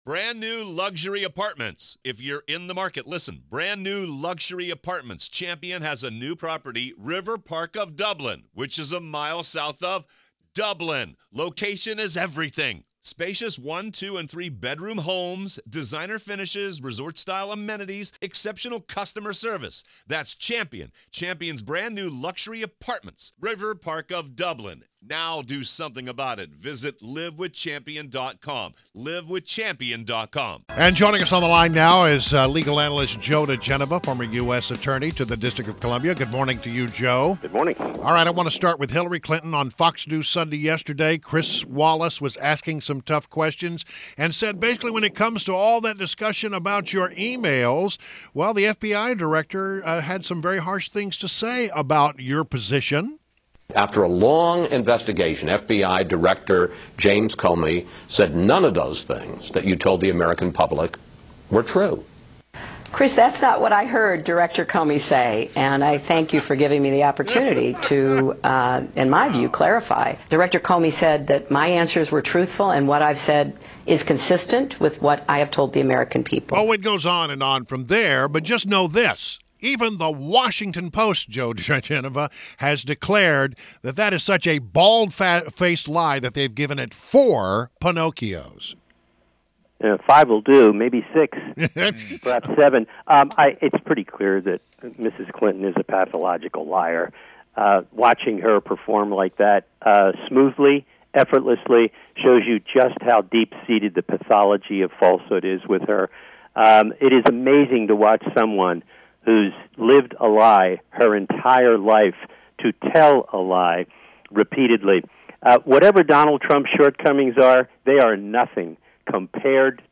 WMAL Interview - Joe Digenova - 8.1.16